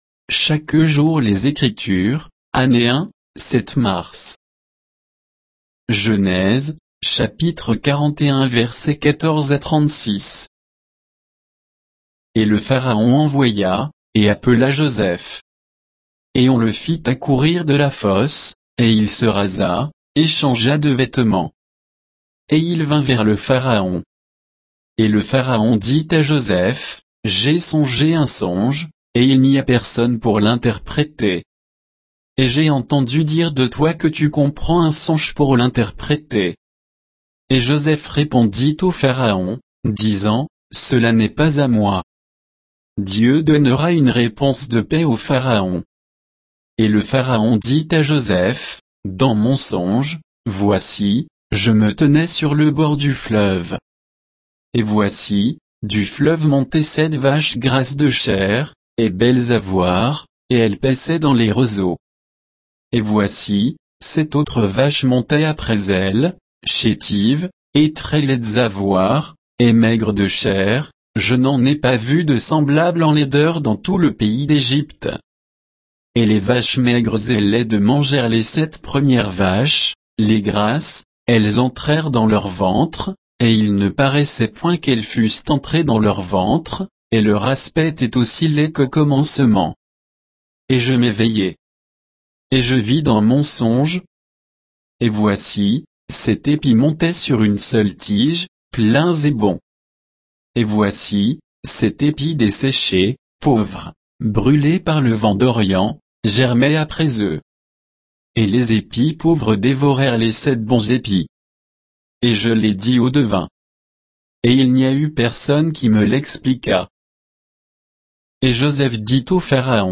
Méditation quoditienne de Chaque jour les Écritures sur Genèse 41